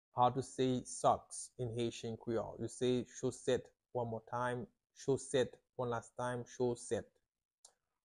How to say "Socks" in Haitian Creole - "Chosèt" pronunciation by a native Haitian tutor
“Chosèt” Pronunciation in Haitian Creole by a native Haitian can be heard in the audio here or in the video below:
How-to-say-Socks-in-Haitian-Creole-Choset-pronunciation-by-a-native-Haitian-tutor.mp3